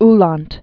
(länt), Johann Ludwig 1787-1862.